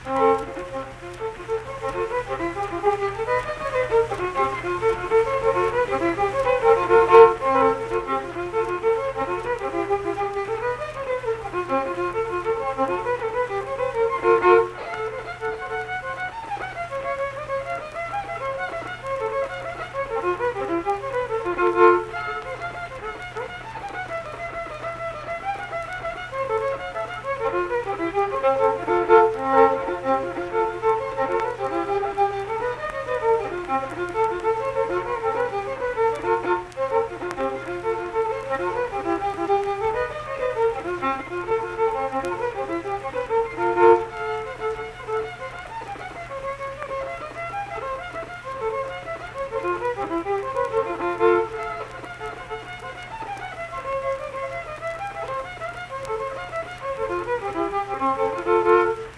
Instrumental Music
Fiddle
Saint Marys (W. Va.), Pleasants County (W. Va.)